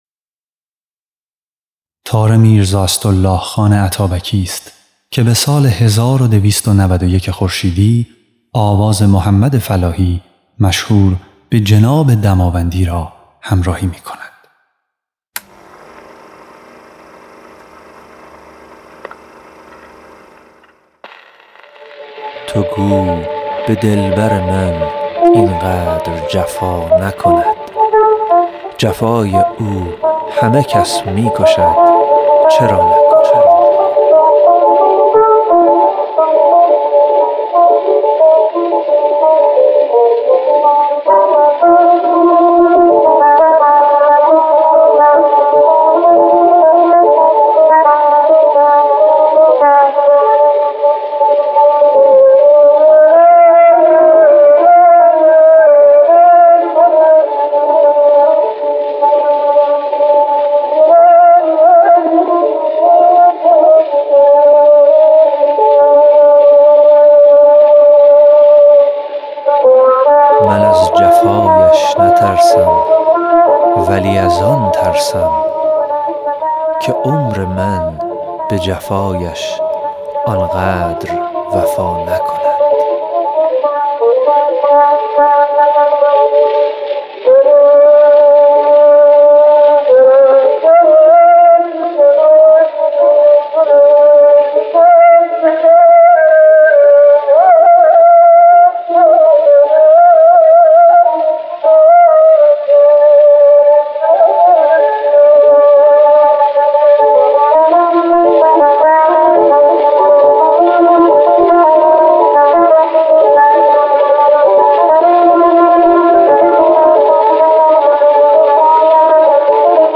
خواننده
نوازنده تار